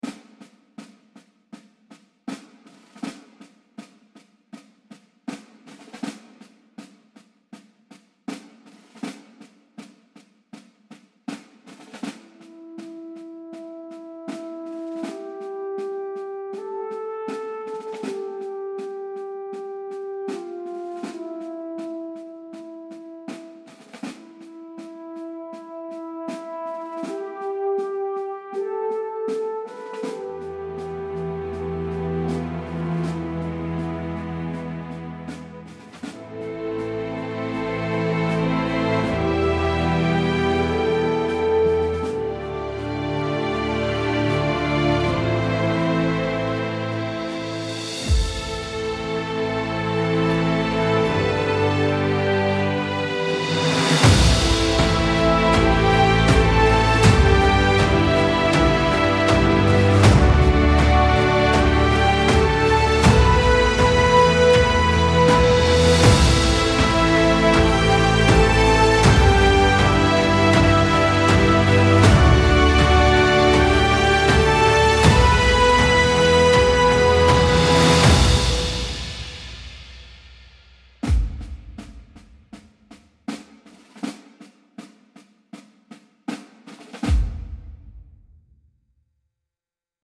military-inspired slow march with snare drum, bugle and strings